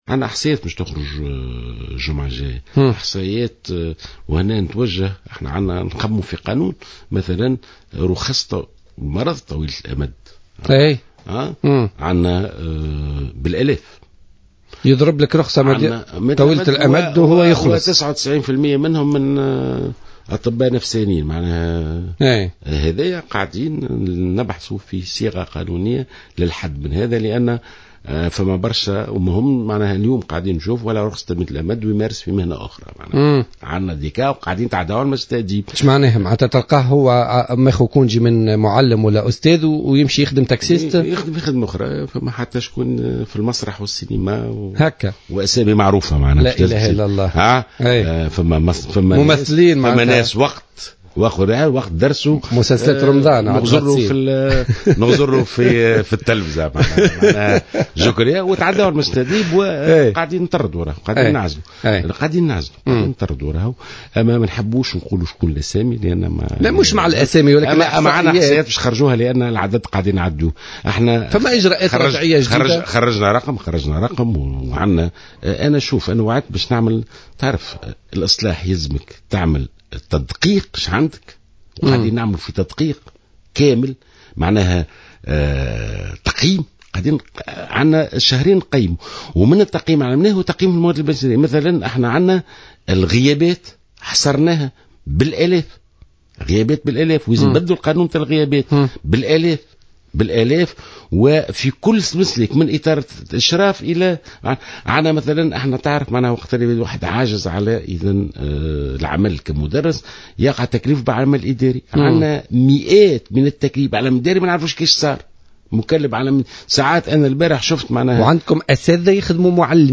كشف وزير التربية والتعليم ناجي جلول لبرنامج "بوليتيكا" على "جوهرة أف أم" اليوم الاثنين 18 ماي 2015 أن هناك عدد من المدرسين يمتهنون أنشطة أخرى بالتوازي مع مهنة التدريس مما يضطرهم إلى تقديم أجازات وهمية مطولة.